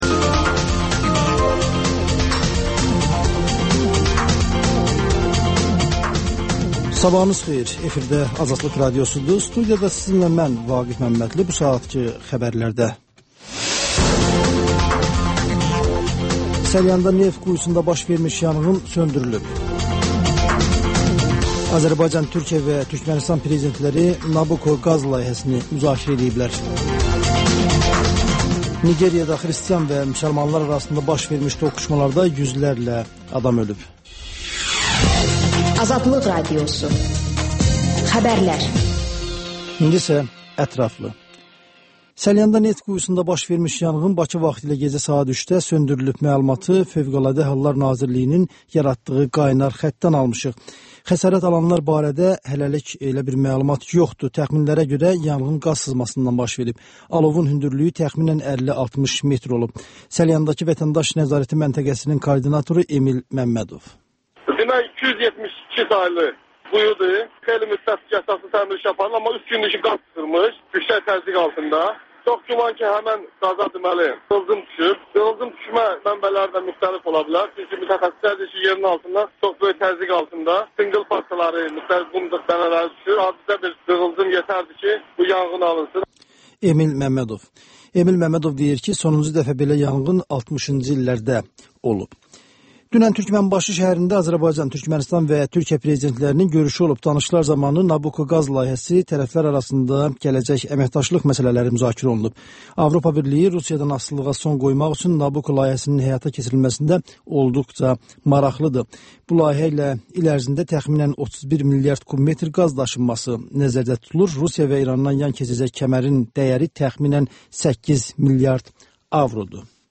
Xəbərlər, QAFQAZ QOVŞAĞI: «Azadlıq» Radiosunun Azərbaycan, Ermənistan və Gürcüstan redaksiyalarının müştərək layihəsi, sonda QAYNAR XƏTT: Dinləyici şikayətləri əsasında hazırlanmış veriliş